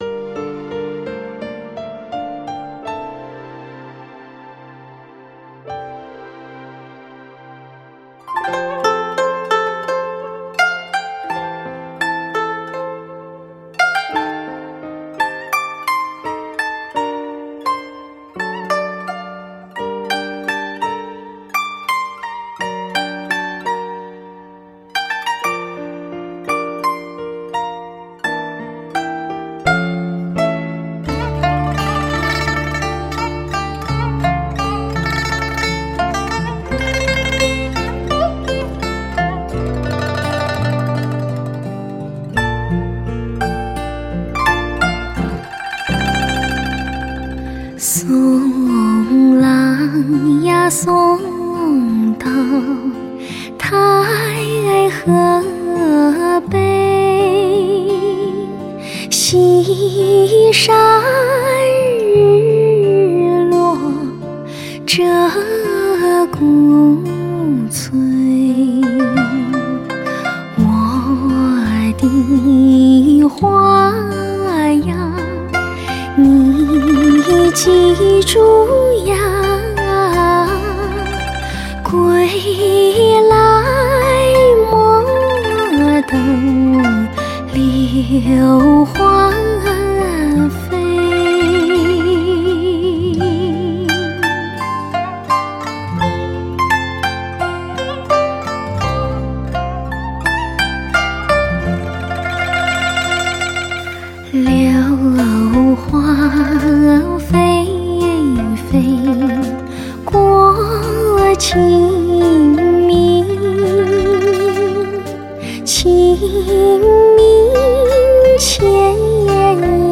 多种音乐风格并存的专辑
色，伴奏乐器的演出精湛，乐器的位置分布准确，人声细致绝伦，非常通透。
阔，动态极为强劲，有爆到落花流水的刺激感。所有演出的男女歌手及乐师均造诣非凡。
歌者深情的演绎，像弦在心上拨动，深入人心，是一张非常超值的示范赏乐用碟！